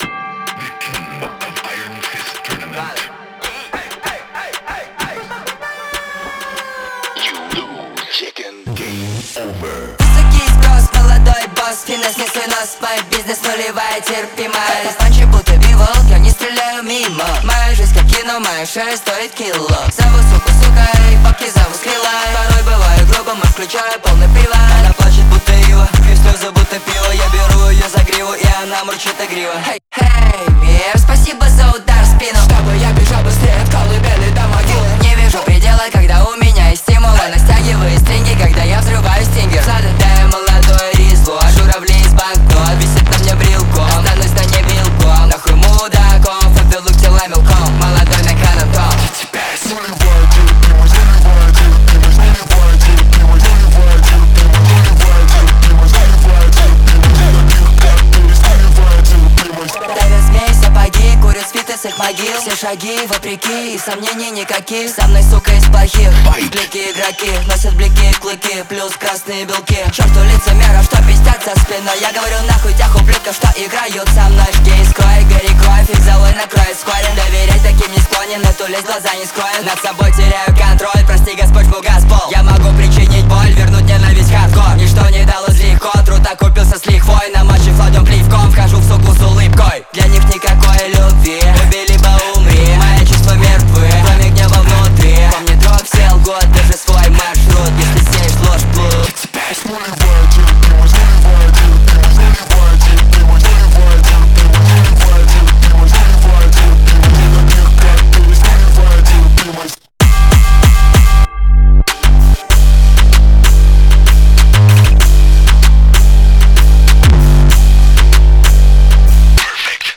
Качество: 320 kbps, stereo
Рэп